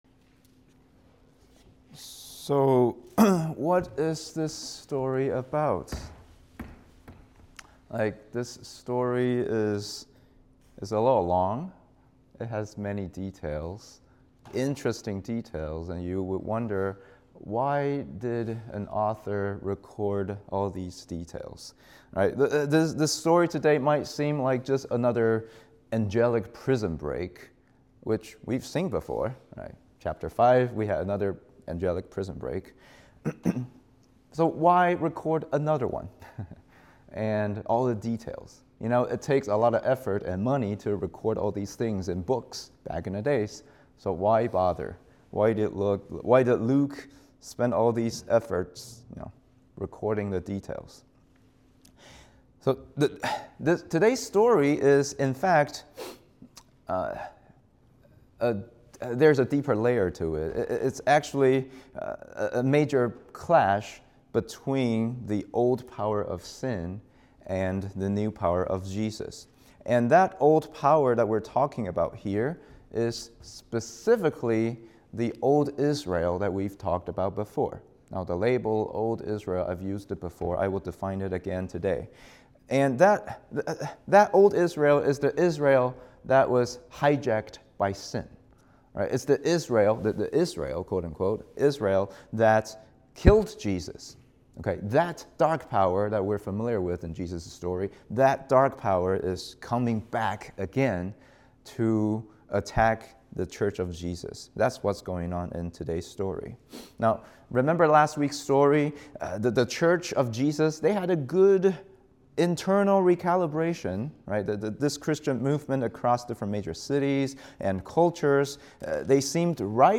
English Sermon